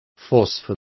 Complete with pronunciation of the translation of phosphors.